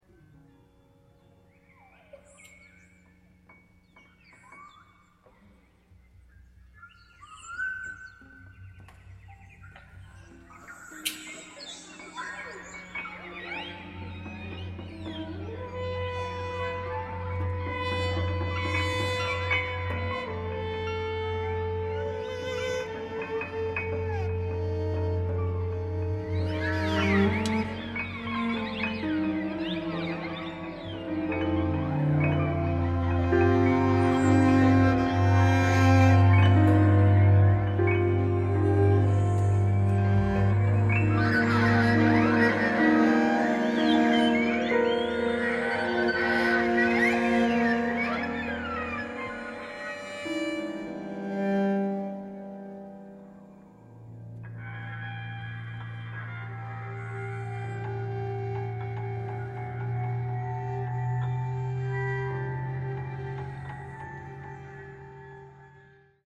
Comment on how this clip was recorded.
recorded October 1, 2024 in Jordan Hall, Boston